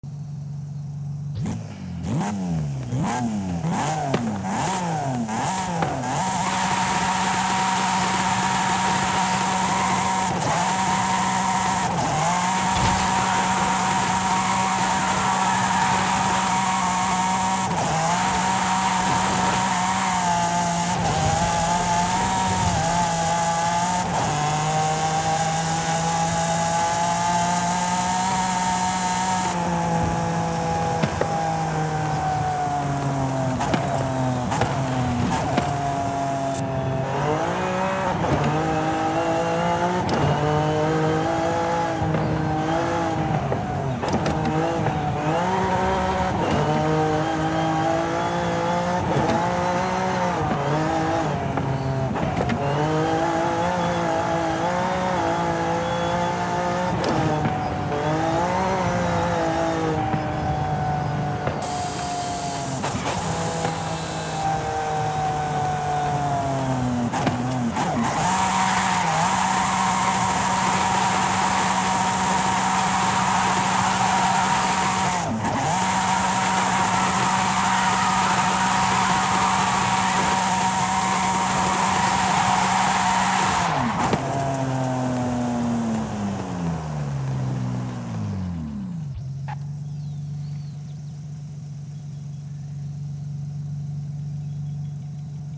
Formula Drift #64 Nissan 370Z 2018 Exhaust Sound